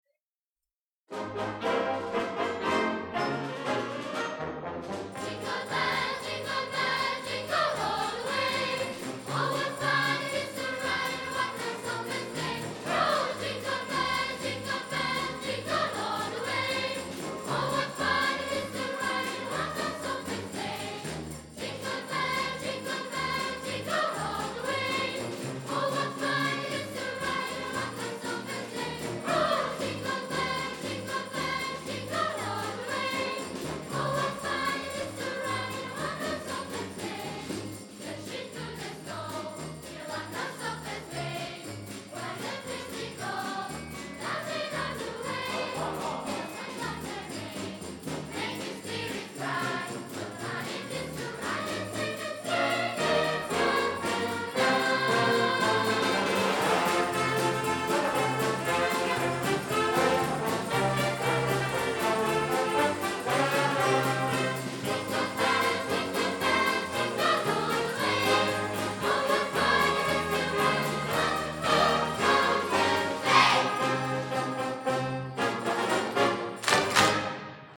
Big-Band Ritmo-Sinfonica "Città di Verona" - EVENTI 2007
Verona Teatro Camploy : Concerto di Natale                      Le canzoni natalizie cantate dal coro